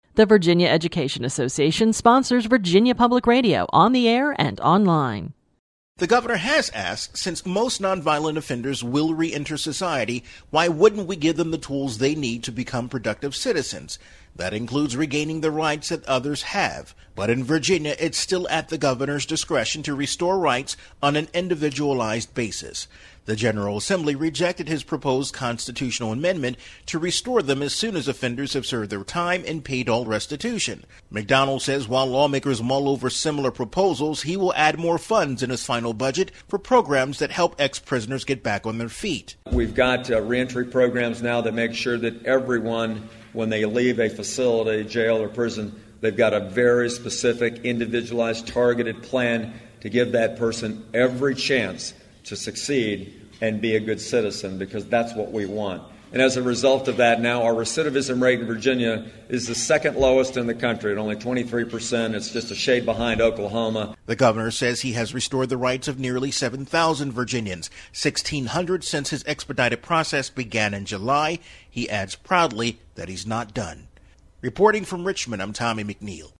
This entry was posted on October 28, 2013, 10:46 am and is filed under Daily Capitol News Updates.